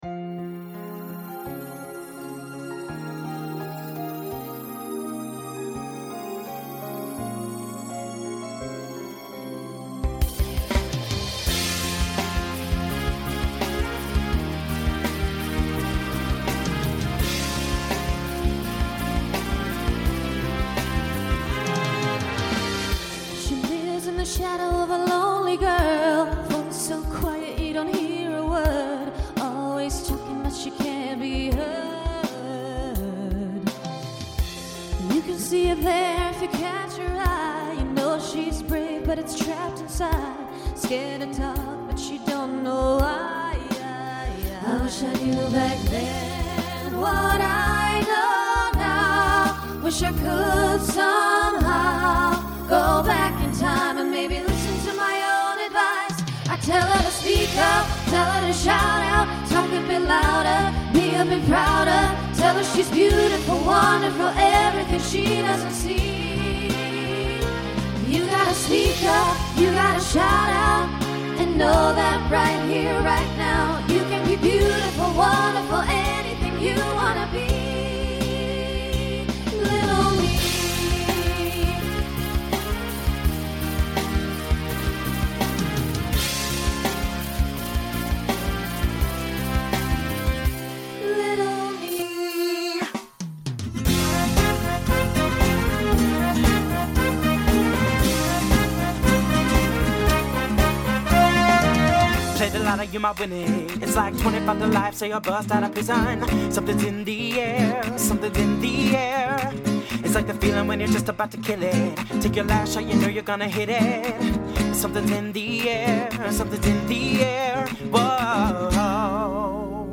SSA/TTB
Voicing Mixed Instrumental combo Genre Pop/Dance